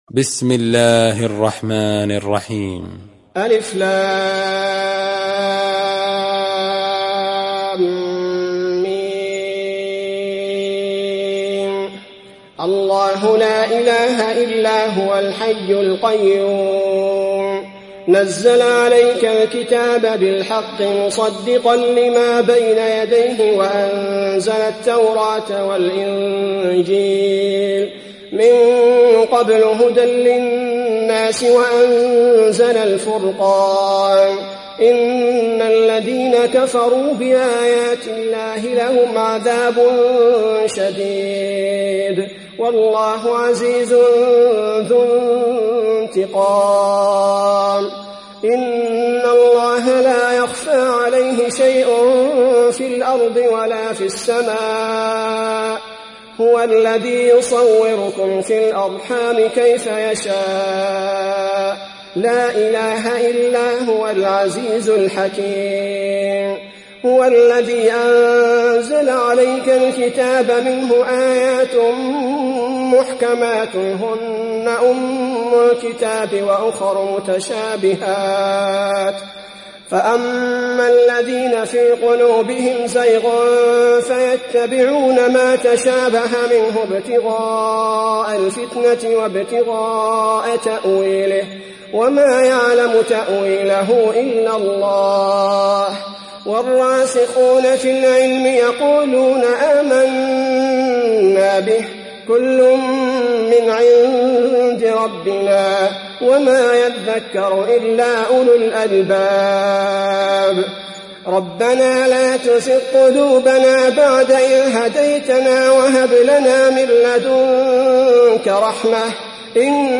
روایت حفص از عاصم